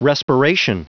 Prononciation du mot respiration en anglais (fichier audio)
Prononciation du mot : respiration